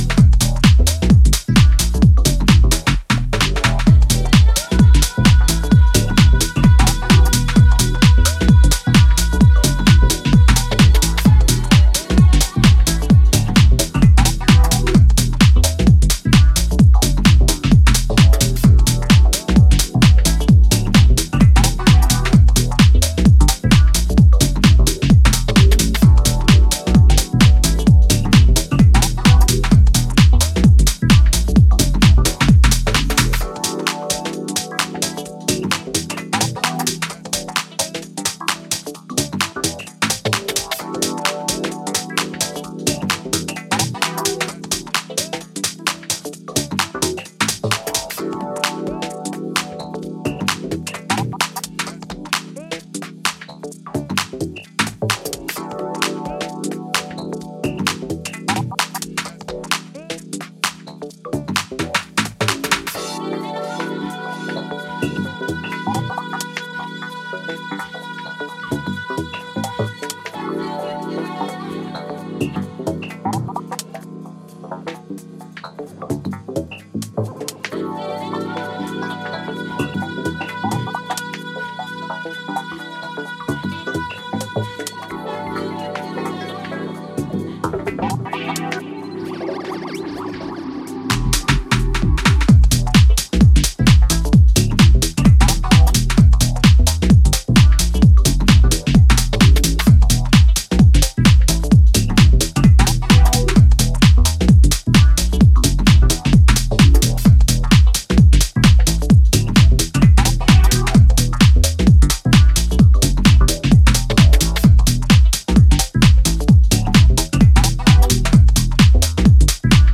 charging with a powerful vibe and ocean energy
showing real underground sound
where everything is moving in steady but calm rhythm.